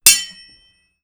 SwordHit.wav